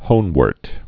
(hōnwûrt, -wôrt)